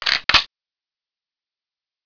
The reload sound could use a little more beef in some parts.
sound part here sounds a little weak. Maybe you could use something
micro_lever.wav